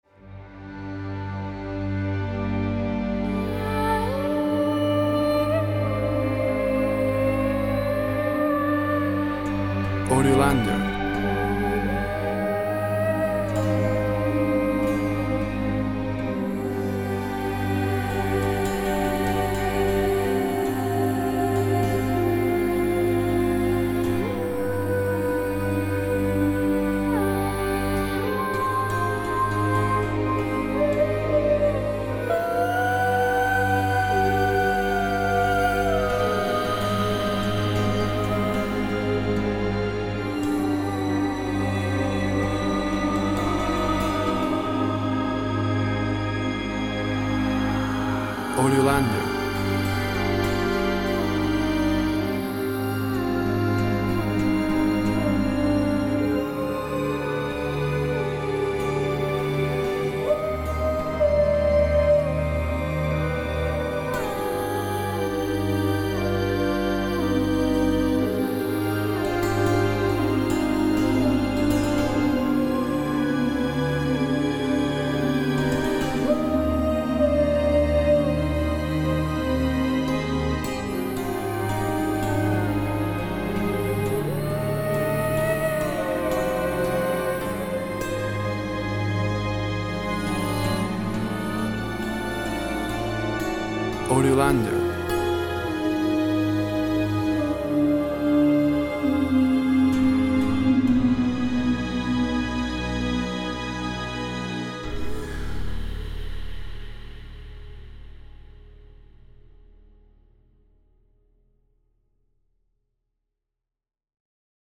A dark fantasy track with ethereal bewitching vocals.
Tempo (BPM) 60/72